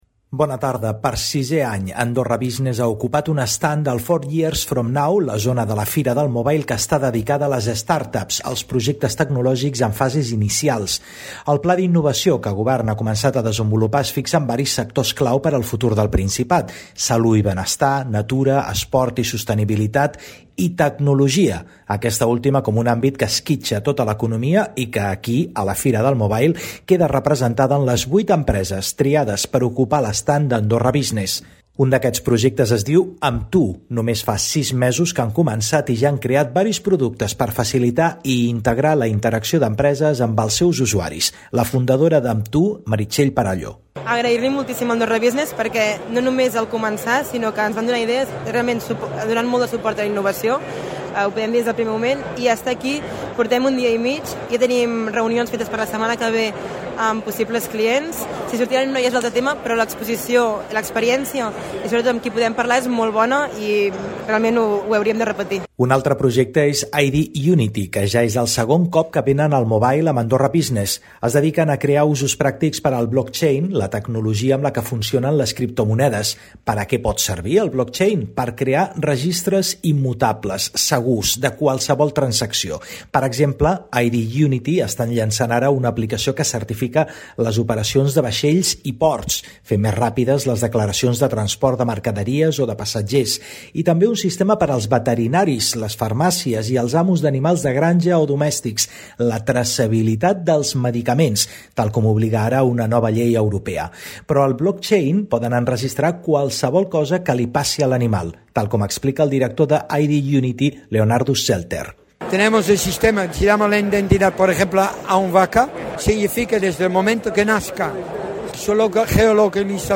Hem parlat amb representants de tres d’elles: